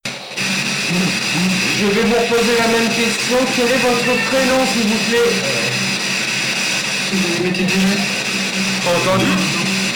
sequence-houhou-on-est-la-ya-quelquun-ralenti.mp3